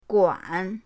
guan3.mp3